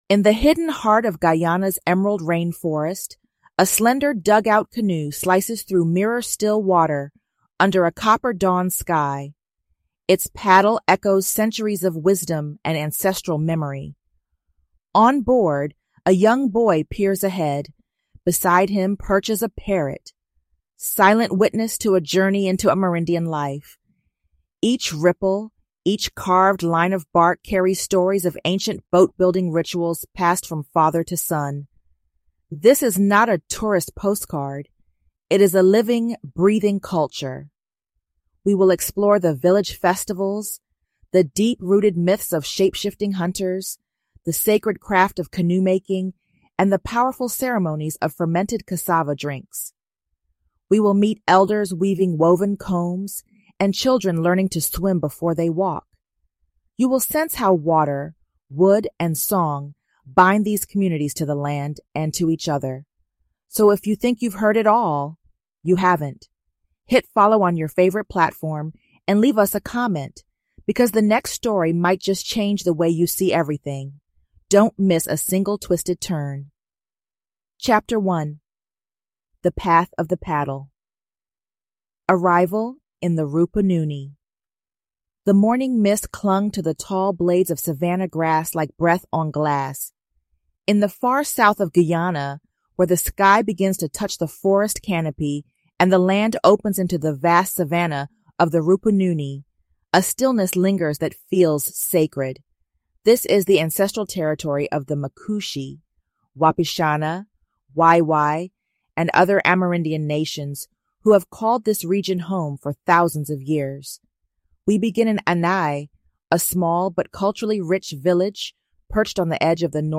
We follow the voices of Indigenous elders and youth as they share how culture, land, and identity remain rooted in the rivers and rainforests of South America’s only English-speaking countr